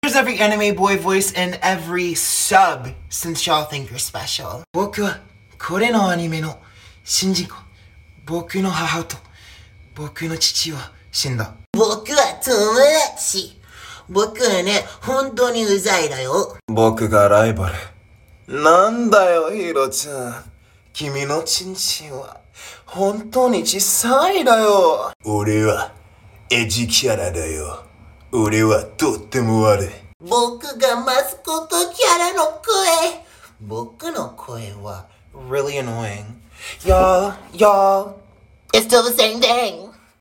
Every Anime Boy voice in sound effects free download
Every Anime Boy voice in every SUB to ever exist OOP ✋🤪